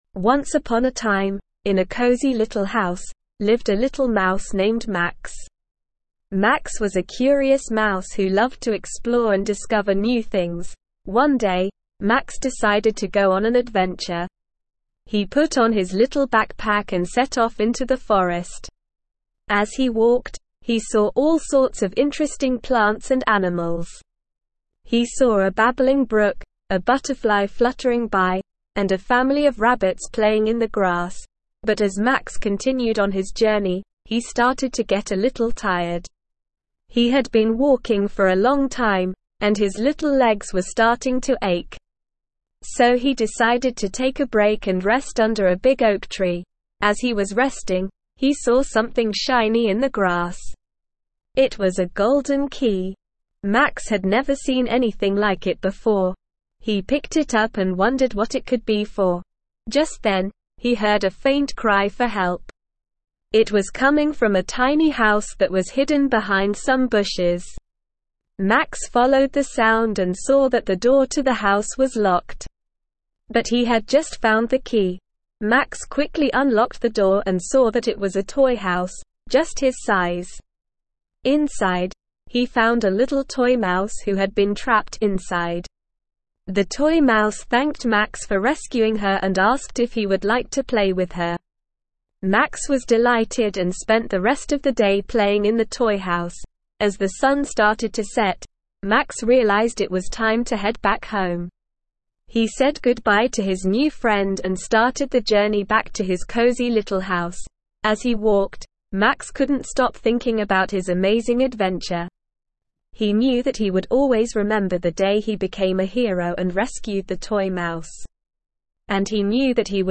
Slow
ESL-Short-Stories-for-Kids-SLOW-reading-Max-the-Little-Mouse.mp3